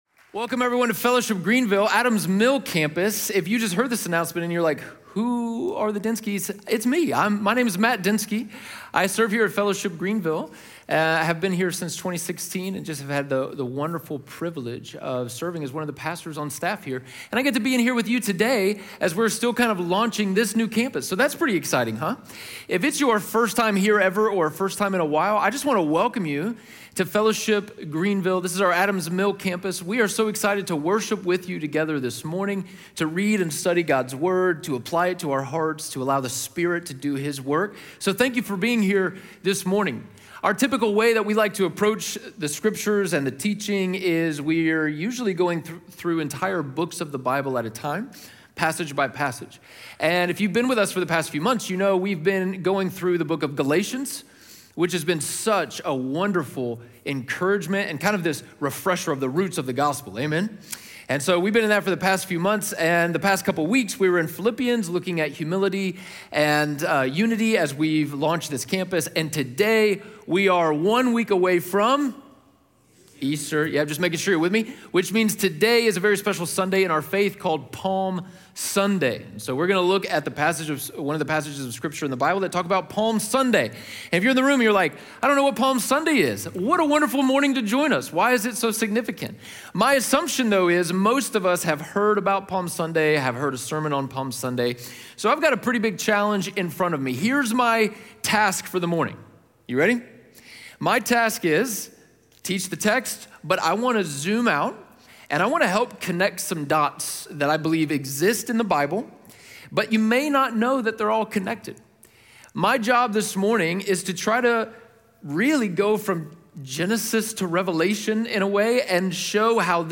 Matthew 21:1-17 Audio Sermon Notes (PDF) Ask a Question PASSAGE GUIDE Jesus’ entry into Jerusalem marks a profound moment of tension and irony—he is “kinged” on Sunday and crucified by Friday.